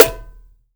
SNARE.1.NEPT.wav